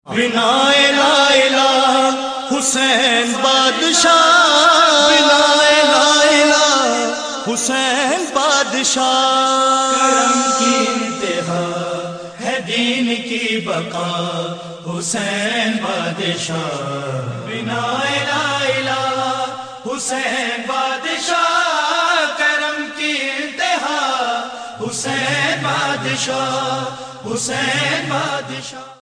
Nohay RingTones